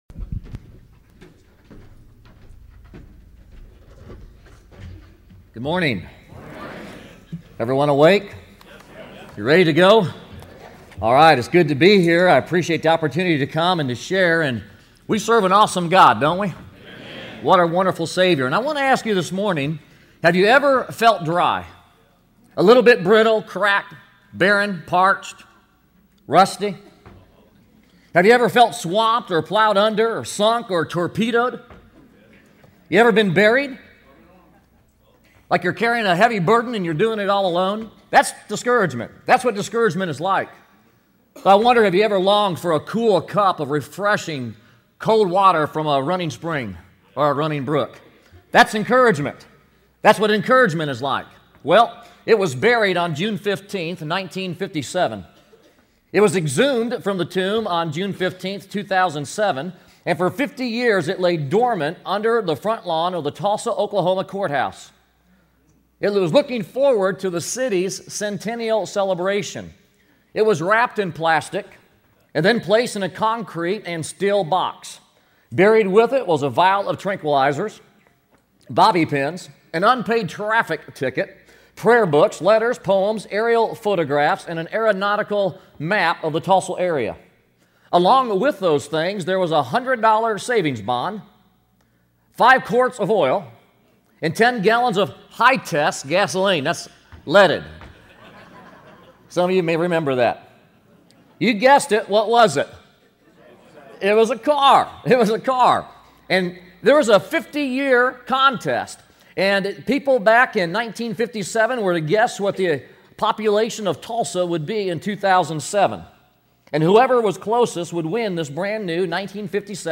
Todays Featured Audio Sermons